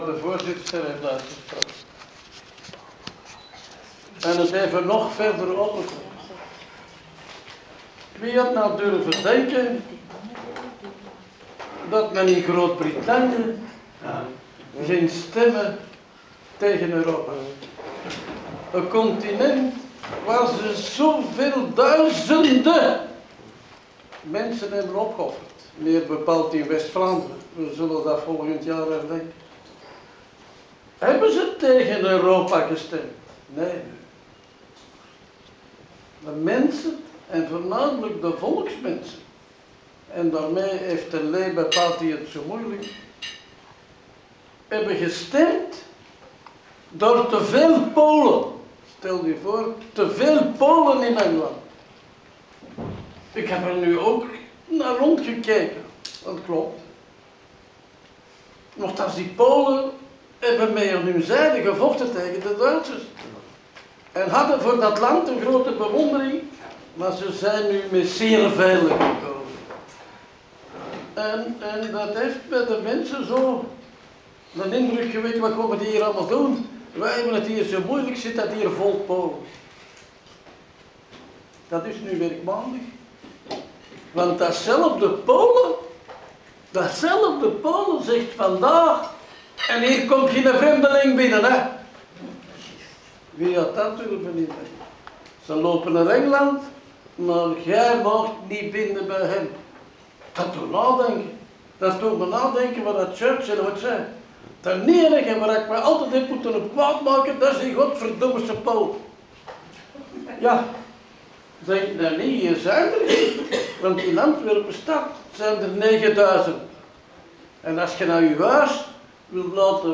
Herdenking Ravensbrück Antwerpen - 02/10/2016
Toespraak oud-burgemeester Cools (audio)
De oud-burgemeester Bob Cools spreekt
Bob-Cools-toespraak.WAV